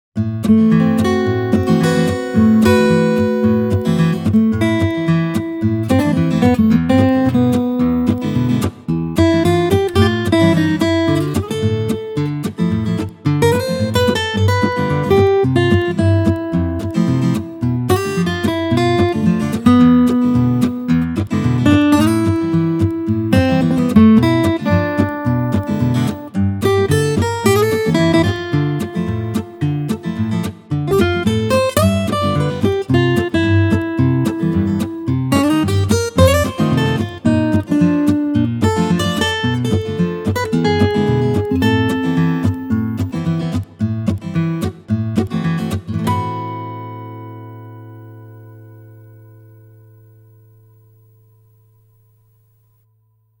Famous, bright, and unmistakable Taylor guitar sound
Solo